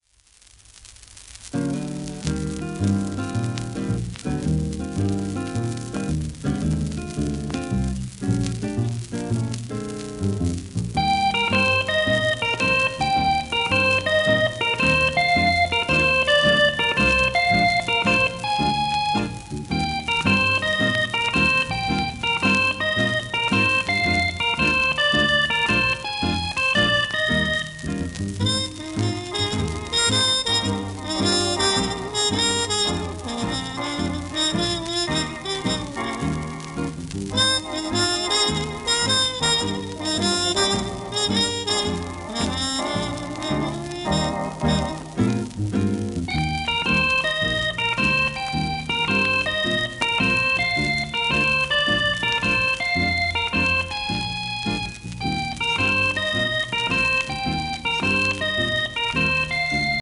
1952年頃の録音